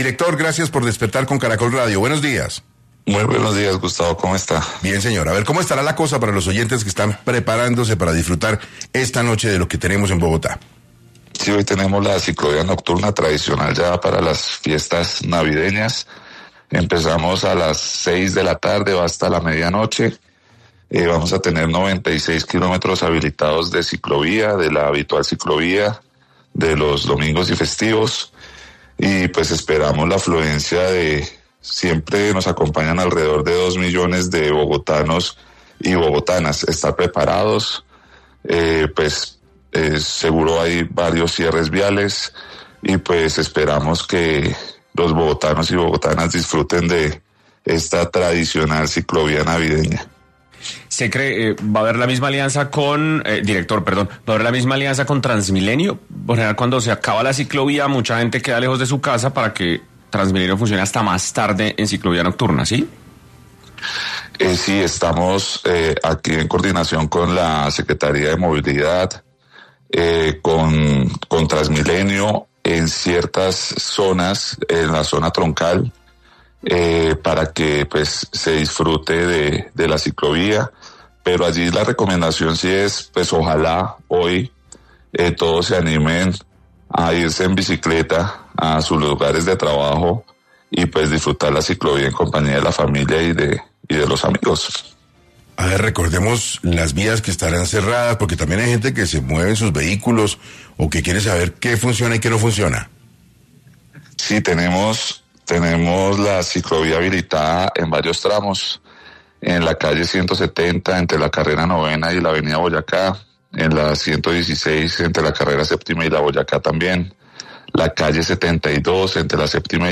El director del Instituto Distrital de Recreación y Deporte, Daniel García, estuvo en 6 AM de Caracol Radio, hablando sobre esta actividad que se realizará bajo el marco de la Navidad.